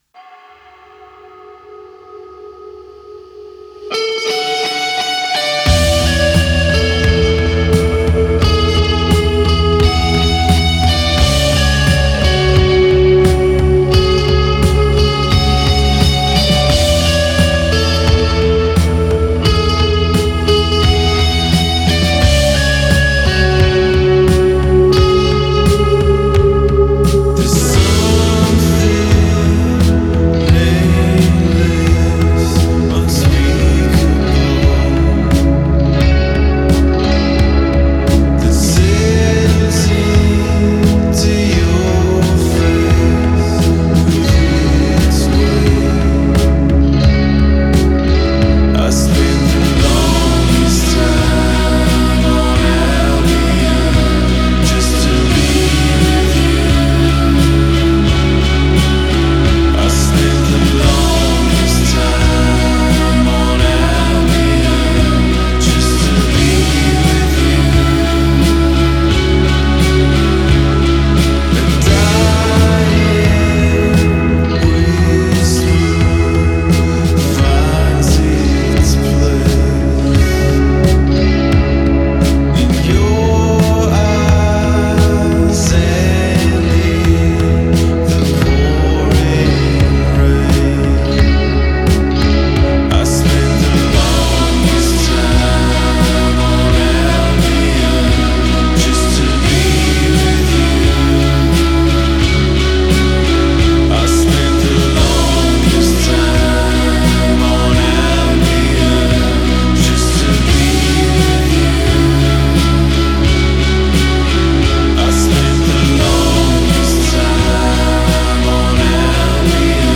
Genre: Indie Pop, Rock, Alternative